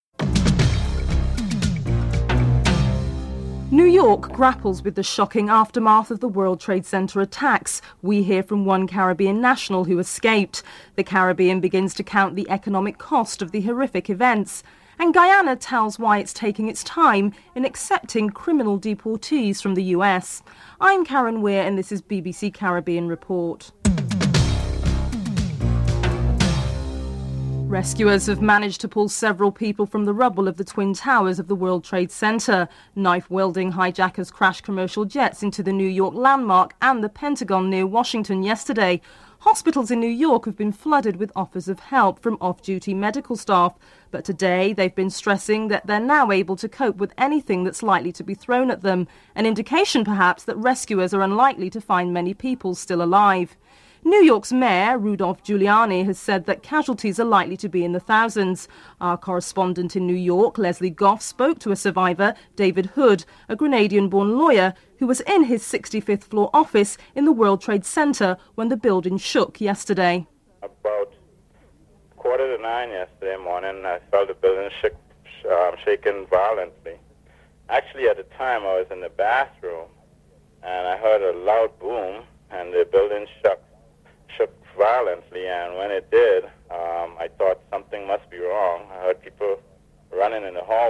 1. Headlines (00:00-00:25)
4. Guyana tells why it is taking its time in accepting criminal deportees from the United States. Guyana Foreign Minister Rudy Insanally is interviewed (11:54-15:13)